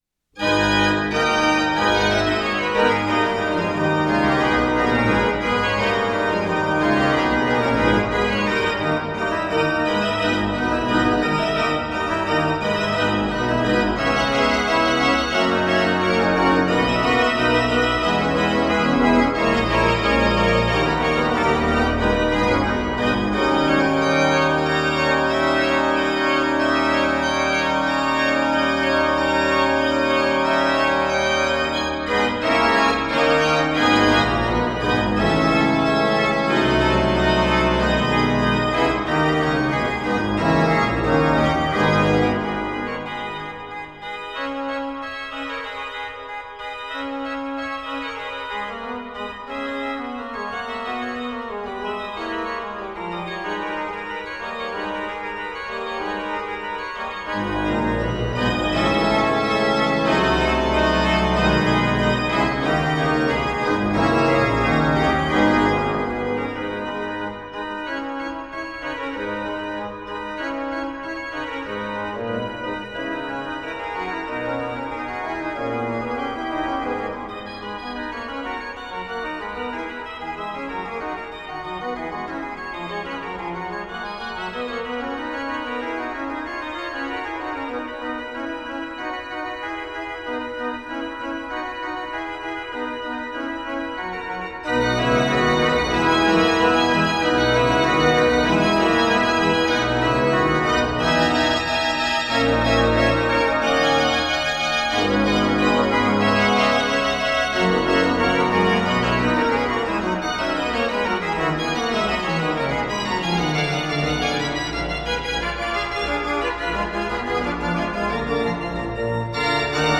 Органный концерт
Органный концерт в католическом приходе Святого Иакова в Южно-Сахалинске